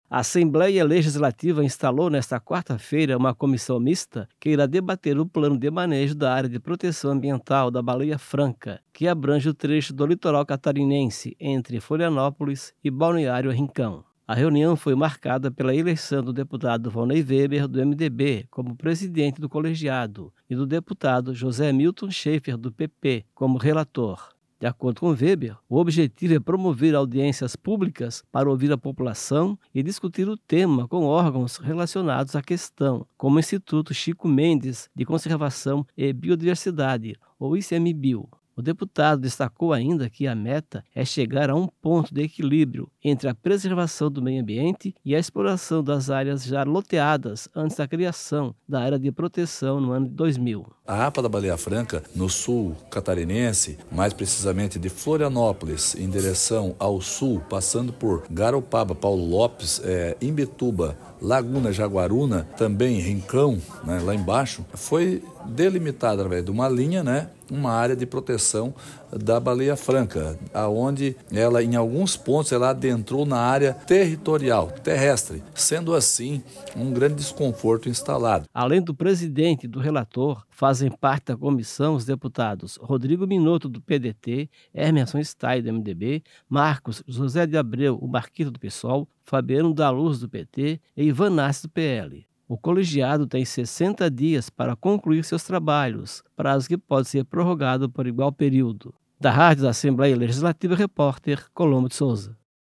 Entrevista com:
- deputado Volnei Weber (MDB).